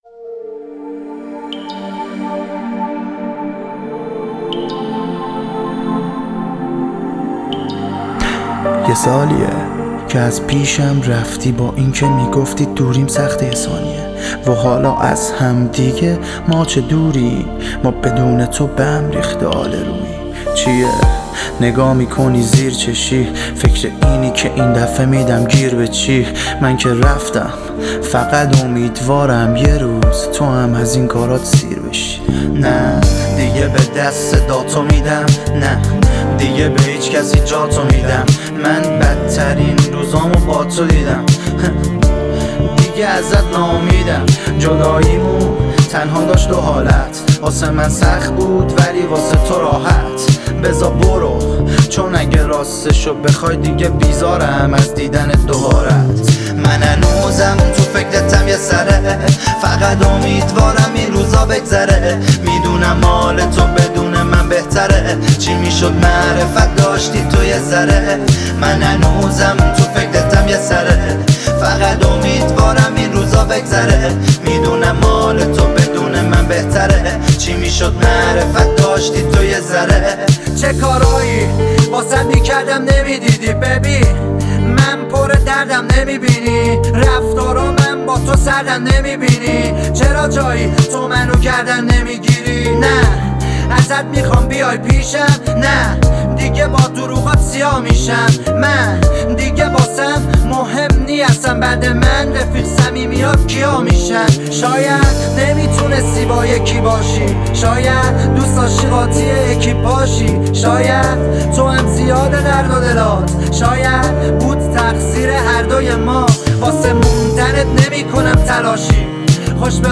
شنیدنی و احساسی